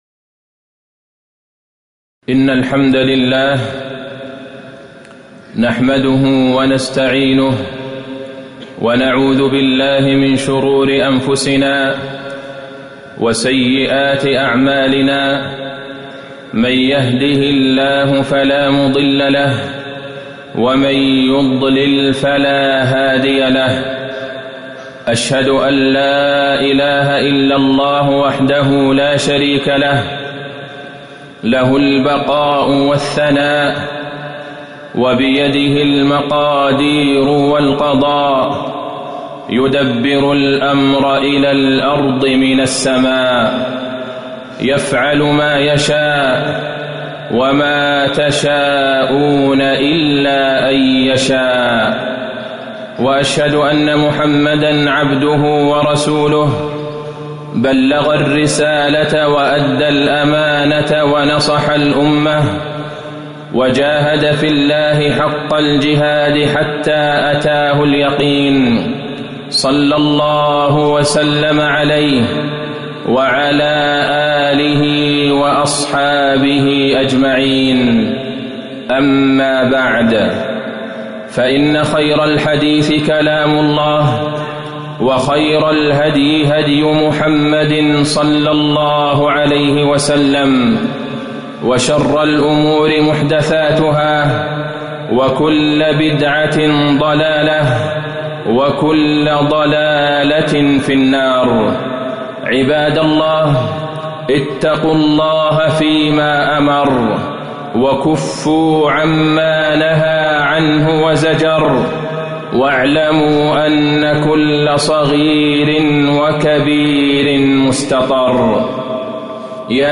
تاريخ النشر ٣ جمادى الآخرة ١٤٤٠ هـ المكان: المسجد النبوي الشيخ: فضيلة الشيخ د. عبدالله بن عبدالرحمن البعيجان فضيلة الشيخ د. عبدالله بن عبدالرحمن البعيجان تكفل الله بالأرزاق